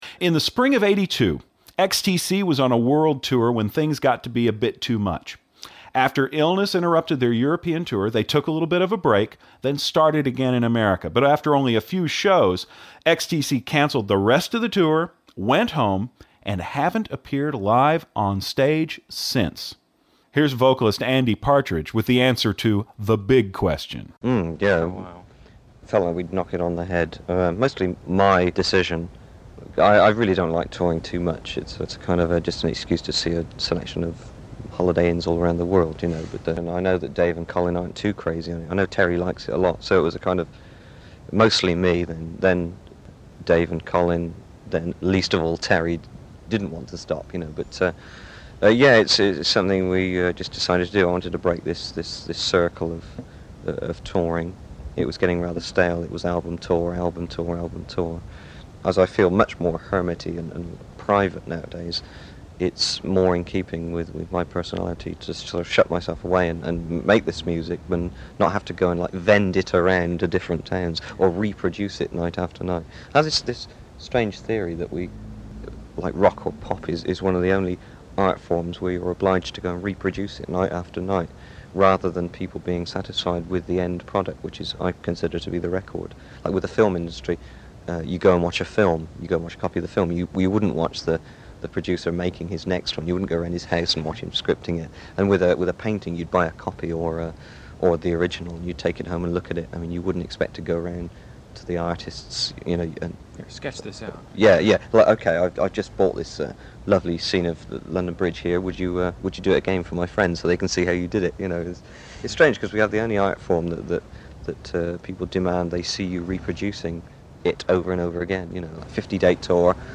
In 1982 XTC took a break from touring and subsequently never played live again. Today we have an interview with